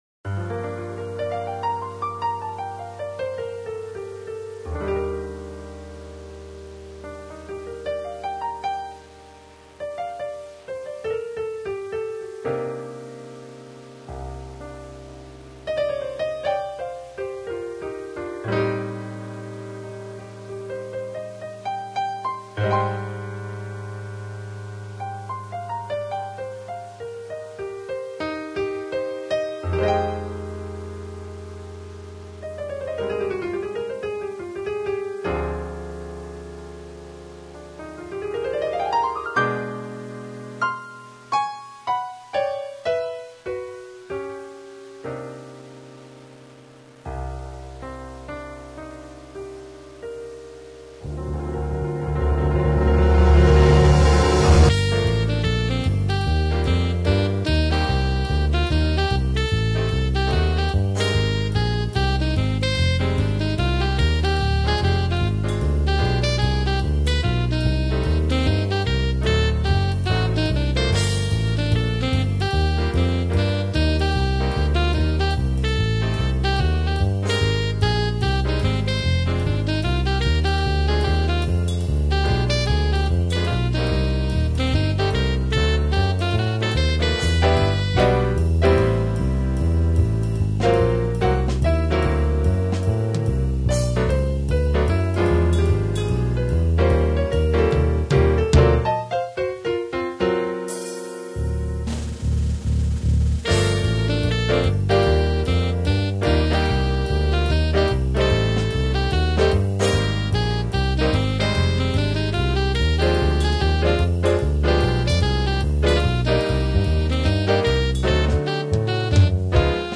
(MIDI sequence)